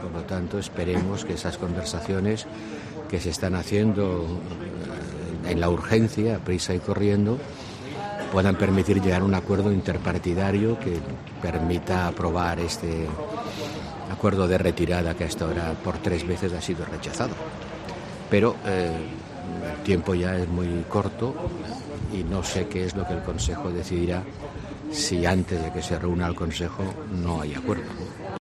"Nunca es tarde si la dicha buena, esperemos que las conversaciones que se están haciendo en la urgencia, deprisa y corriendo, puedan permitir llegar a un acuerdo interpartidario que permita aprobar este acuerdo de retirada que hasta ahora, por tres veces, ha sido rechazado", dijo Borrell a la prensa a su llegada a un Consejo de ministros de Exteriores de la Unión Europea (UE).